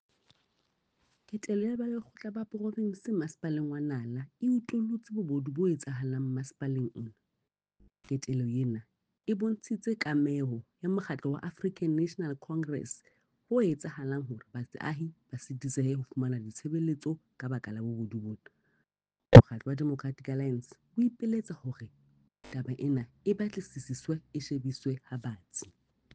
Sesotho soundbite by Cllr Mahalia Kose